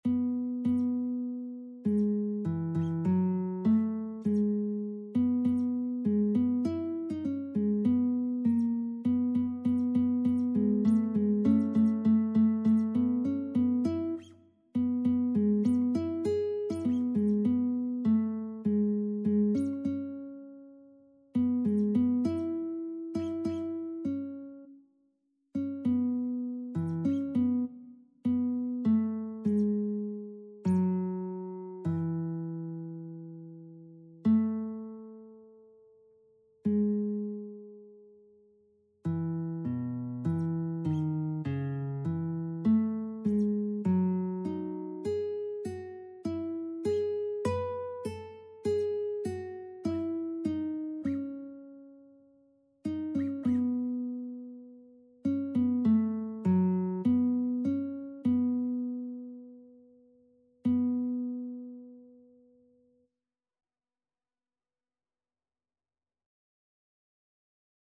Christian
4/4 (View more 4/4 Music)
Classical (View more Classical Lead Sheets Music)